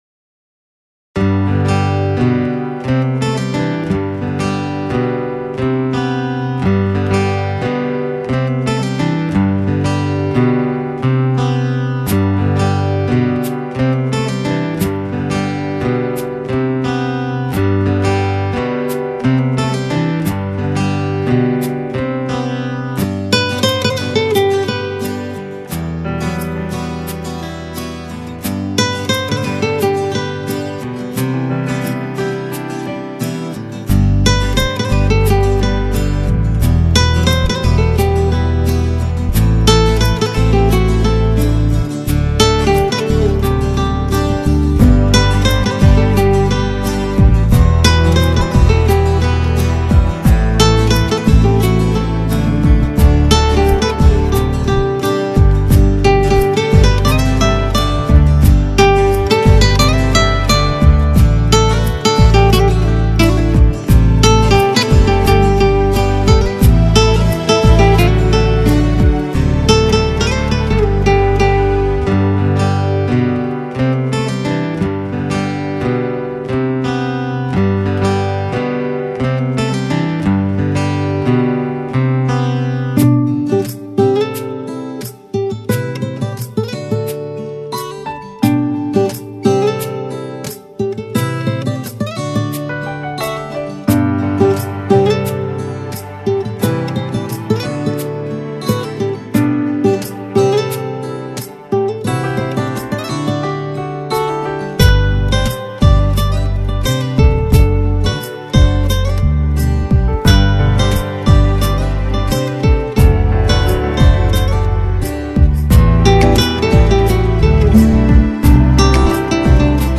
[Artist: Instumental ]
Instrumental Songs